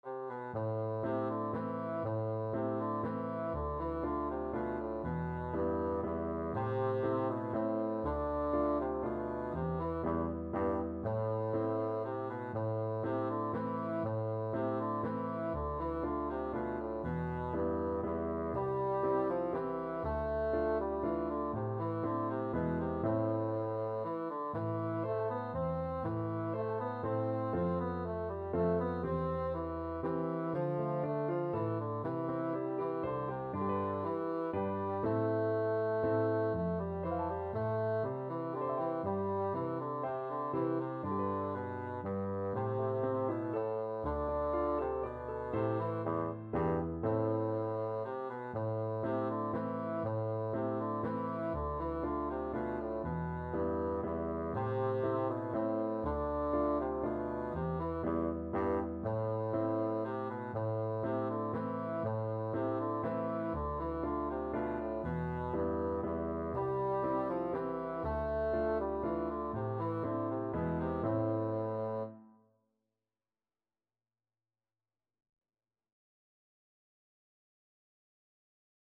Bassoon
A minor (Sounding Pitch) (View more A minor Music for Bassoon )
= 120 Allegro (View more music marked Allegro)
Traditional (View more Traditional Bassoon Music)